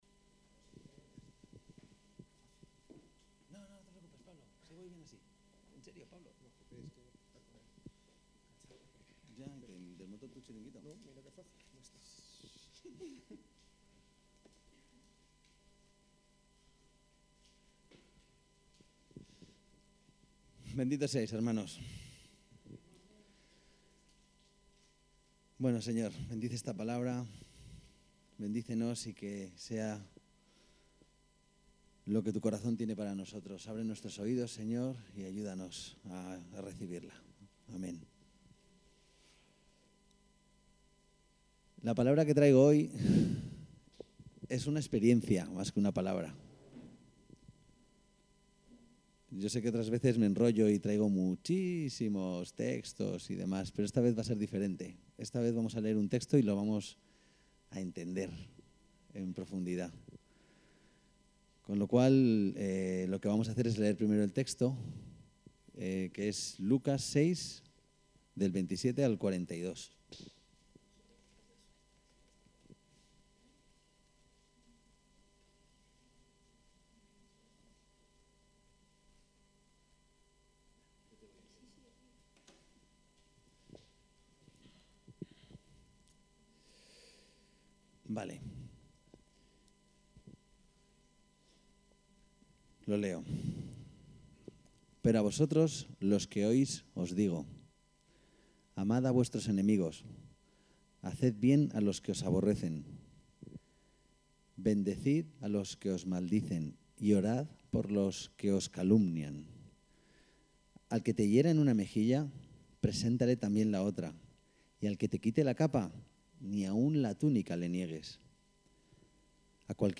Predicaciones